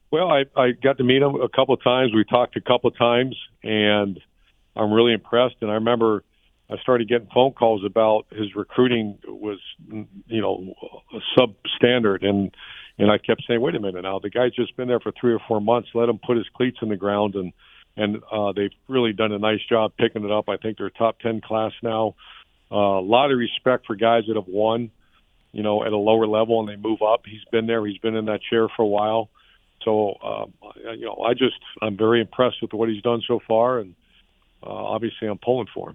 In an interview on Sportscene